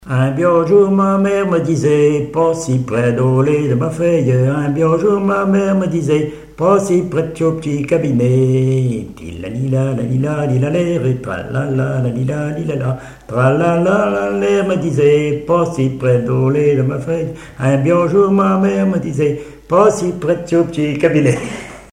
Mémoires et Patrimoines vivants - RaddO est une base de données d'archives iconographiques et sonores.
Couplets à danser
branle : avant-deux
Pièce musicale inédite